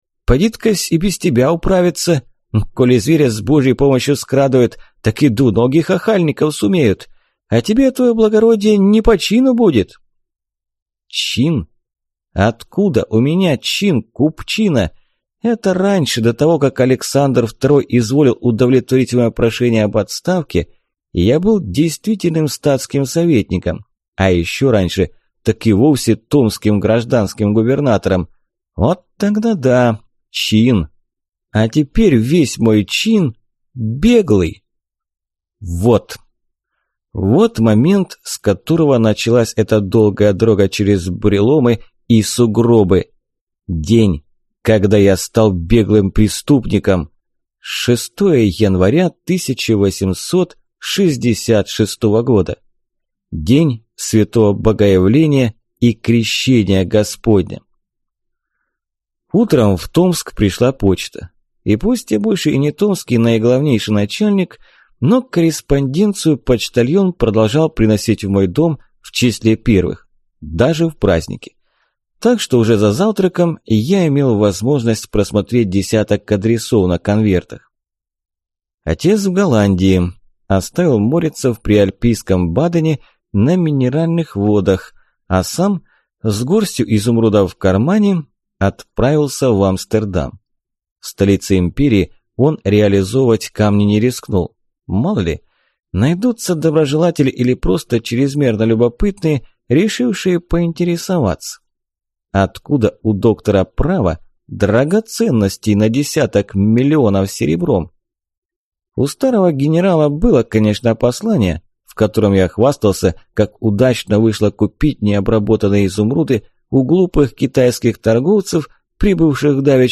Аудиокнига Без Поводыря | Библиотека аудиокниг